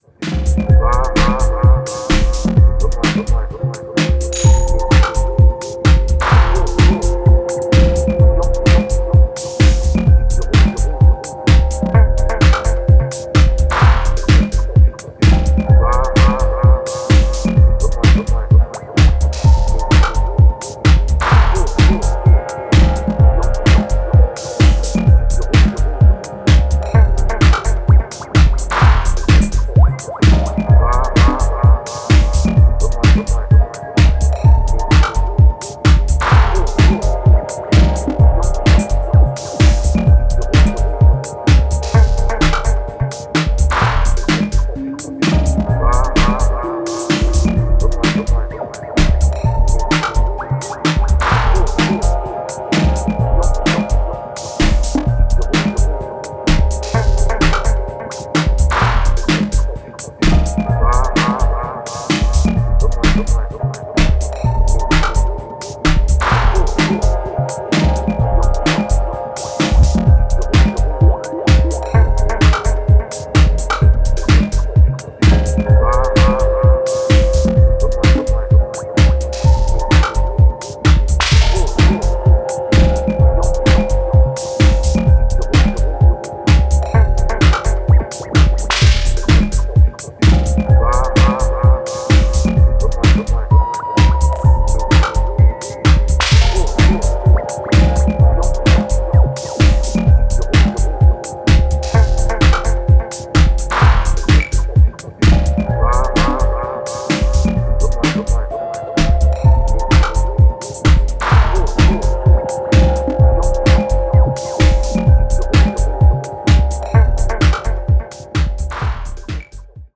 アブストラクトなボトムとウワモノでフロアを黙々とダンスに誘う